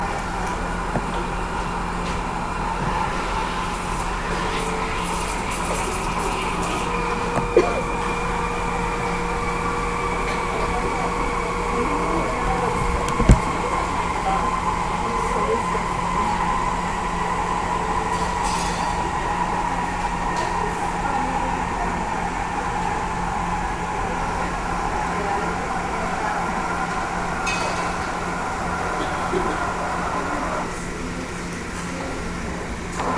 Field Recording
Location — Pura Vida Sounds — Espresso Machine, some conversation